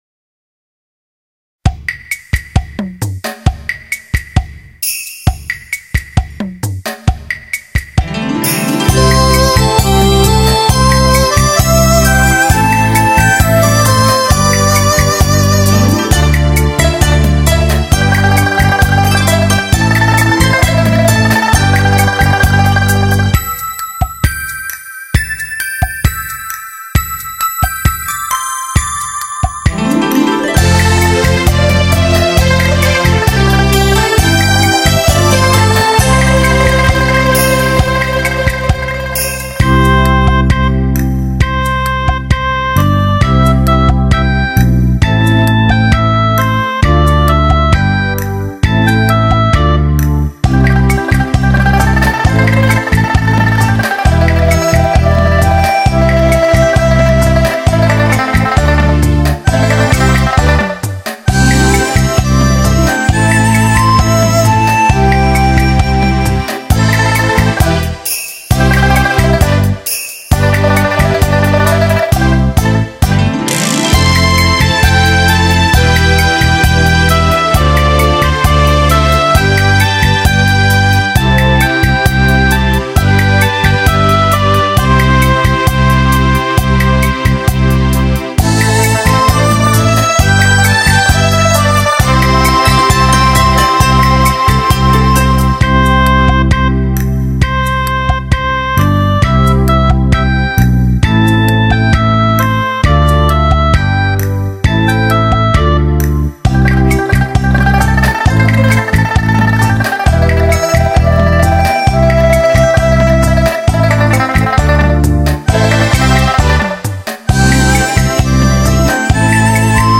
中国佛教音乐是中国音乐文化的重要组成部分，是中华民族的宝贵文化遗产。
其音清新典雅，超凡脱俗，其韵幽远深长，唱者身心合一，物我两忘；